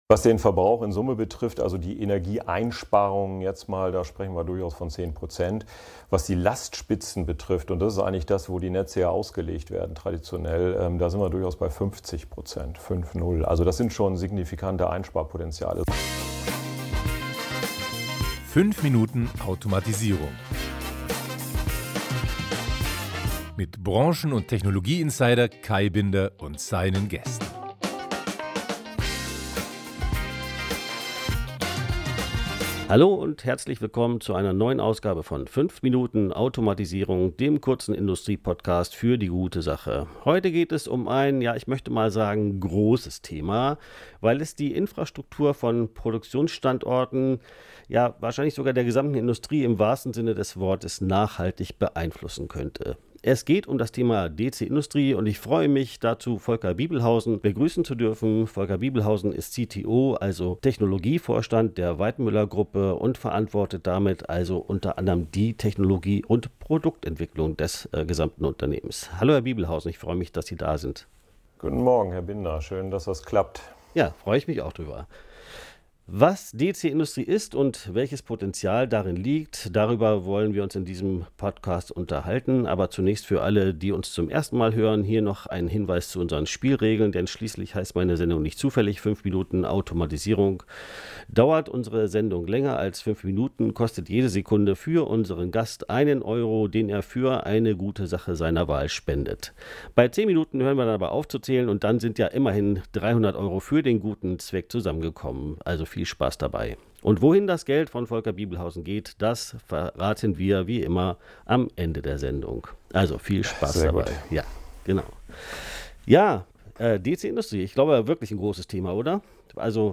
In unserem Gespräch erläutert er, was sich hinter der DC-Industrie und es wird schnell klar, warum man sich dringend mit dem Thema befassen sollte: Es ist nicht nur ein wesentlicher Baustein auf dem Weg in eine nachhaltige Zukunft, sondern kann auch unmittelbar helfen, Lastspitzen signifikant zu senken.